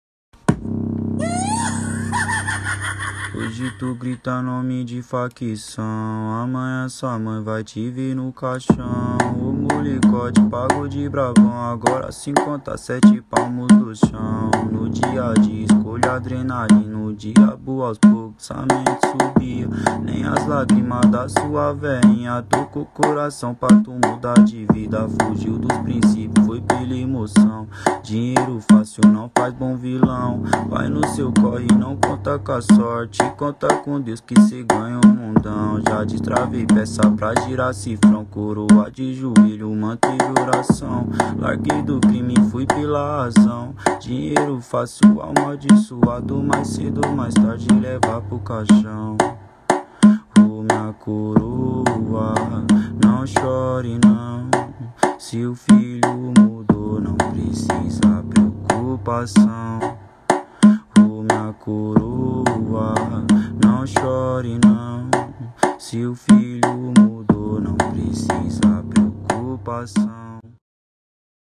2024-08-23 20:57:16 Gênero: Funk Views